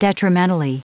Prononciation du mot detrimentally en anglais (fichier audio)